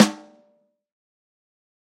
Snare Zion 4.wav